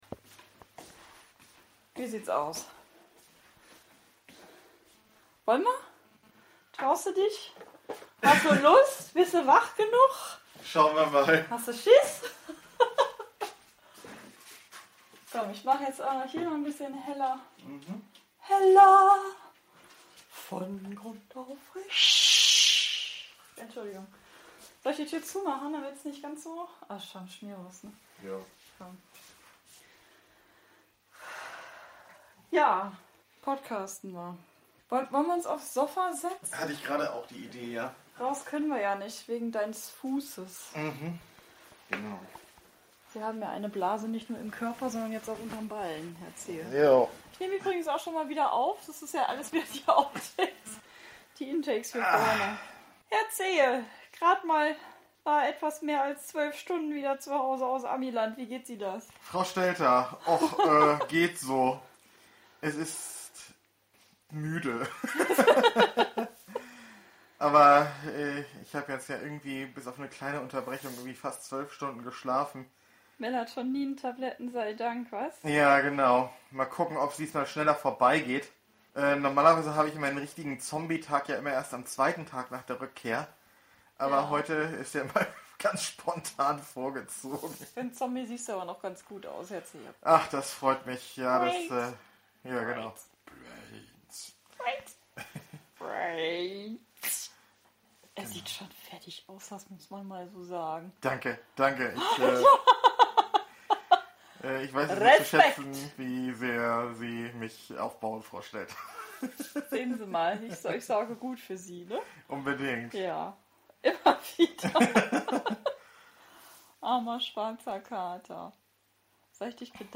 Dafür machen wir uns im Büro breit (Wohnzimmer würde nicht gehen, weil da unsere Vögel leben und lärmen).